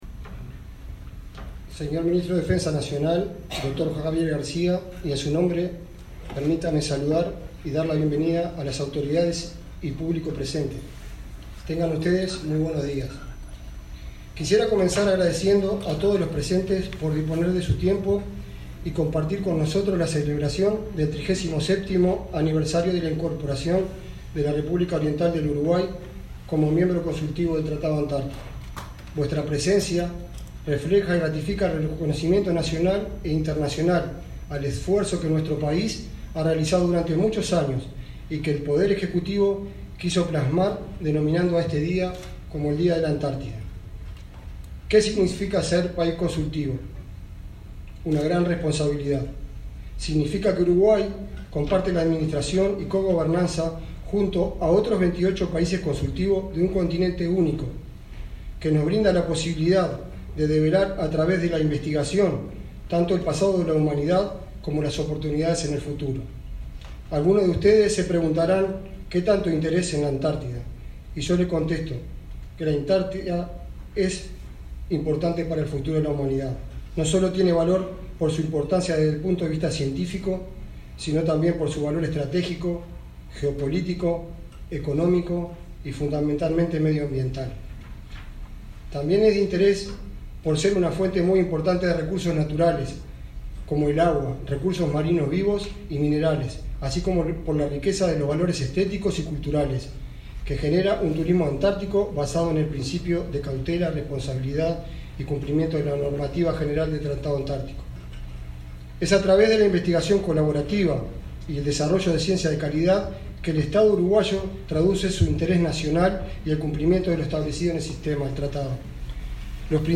Palabras del presidente del Instituto Antártico Uruguayo
Palabras del presidente del Instituto Antártico Uruguayo 11/10/2022 Compartir Facebook X Copiar enlace WhatsApp LinkedIn El presidente del Instituto Antártico Uruguayo, Fernando Colina, fue el orador central del 37.º aniversario del ingreso de Uruguay como miembro consultivo al Sistema del Tratado Antártico.